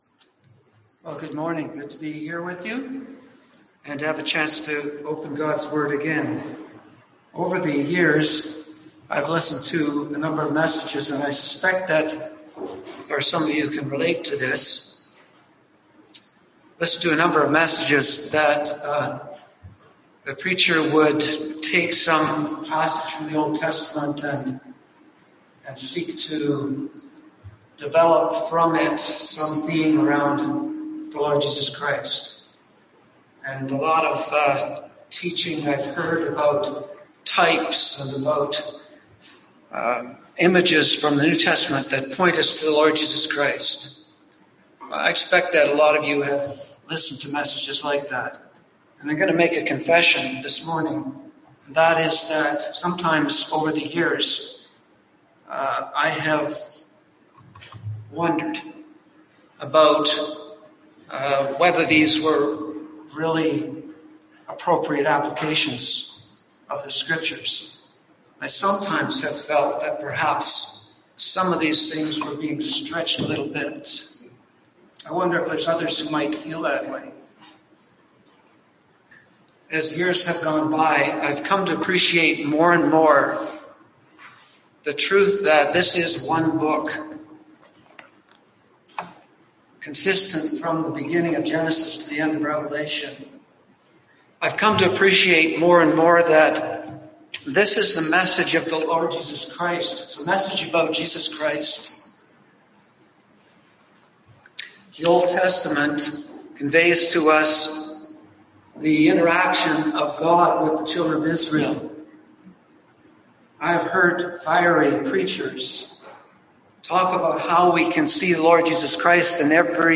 Service Type: Sunday AM Topics: Christ , Messiah , Moses , Prophecy